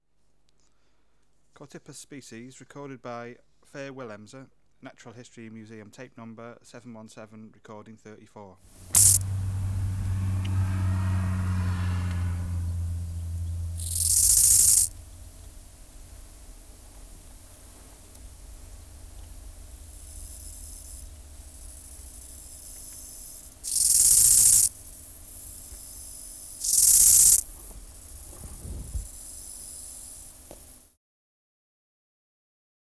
Species: Chorthippus